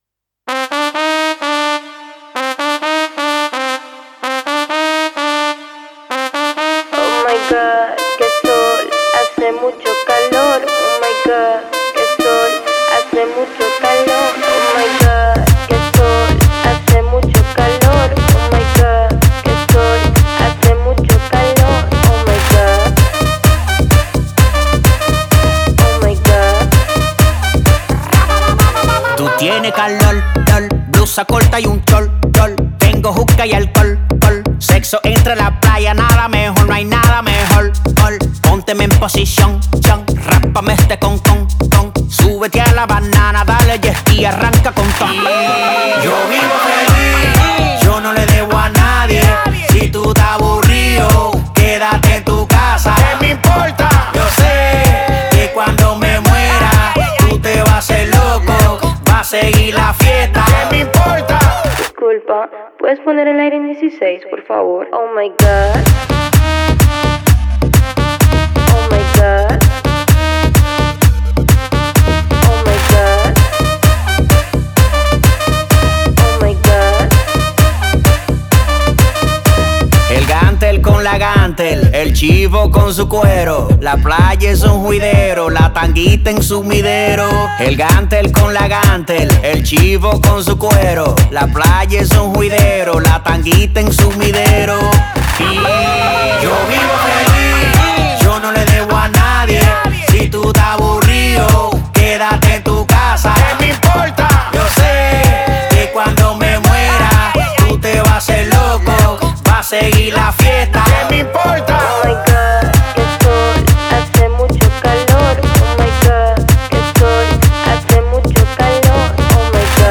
Genre: Latin Pop.